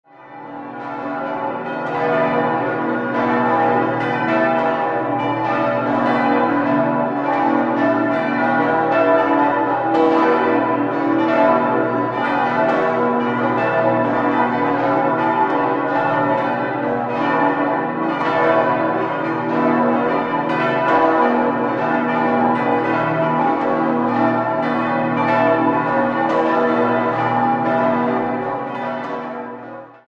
6-stimmiges Gloria-TeDeum-Geläute: h°-cis'-e'-fis'-a'-h'
bell
Im Jahr 2002 wurden die Stahlarmaturen durch solche aus Holz ersetzt und außerdem kam die große Glocke neu hinzu. Nun klingt das Geläute nicht mehr scharf, sondern hat einen homogeneren, weichen Klang. Im Tonbeispiel ist im Hintergrund auch das vierstimmige Geläute (gis'-h'-cis''-e'') der benachbarten Evangelischen Kirche zu hören.